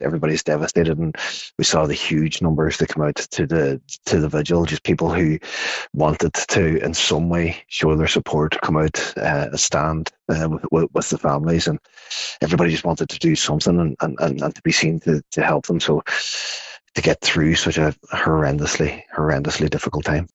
Cathaoirleach of the Inishowen MD, councillor Jack Murray, says the community is rallying around the families: